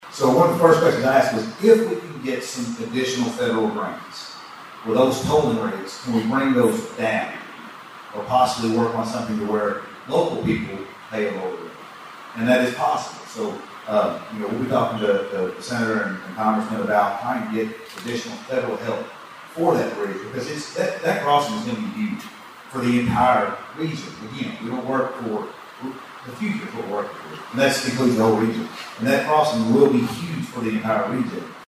Local leaders are actively advocating in Frankfort and Washington, D.C., to secure funding for economic growth, veteran support, and infrastructure projects, which was one of the topics discussed at last week’s State of the Cities and County event.